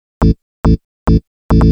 Techno / Bass / SNTHBASS098_TEKNO_140_A_SC2.wav